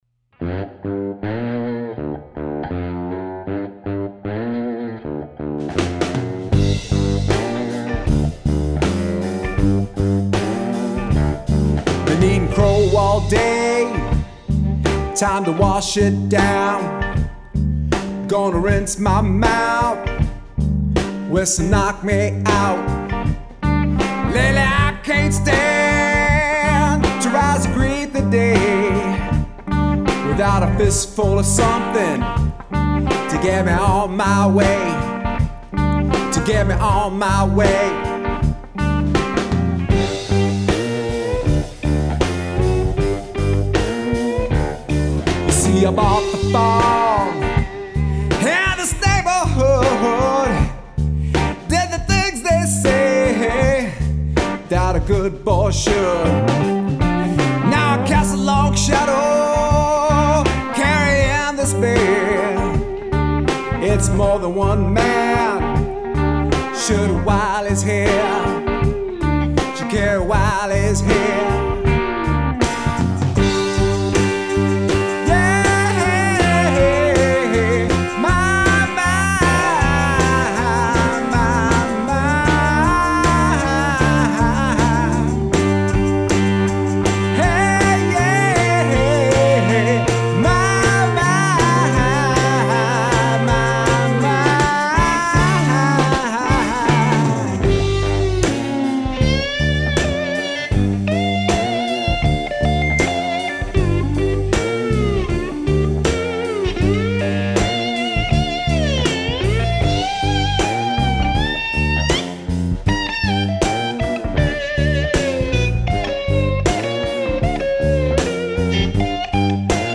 song demo recorded 2018 in Austin, TX
vocals and percussion
guitar and keys
drums
bass